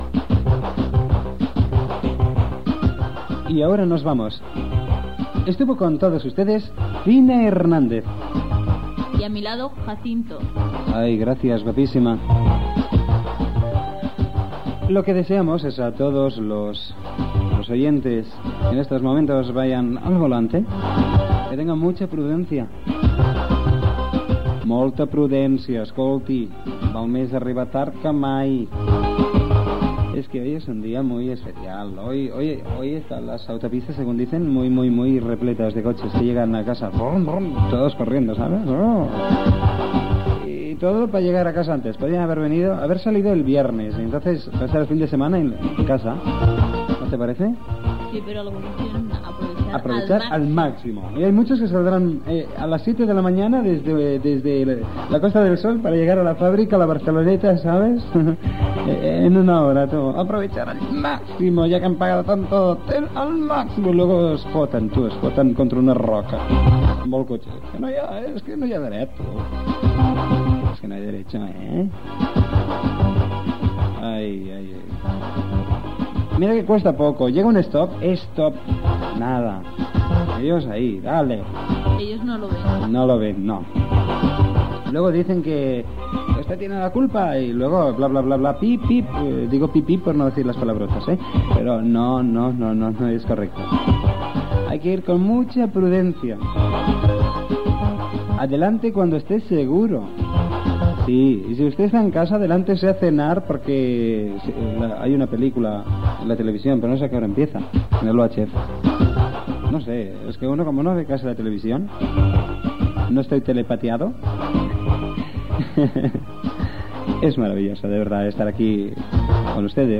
Comiat formula musical i tancament Gènere radiofònic Musical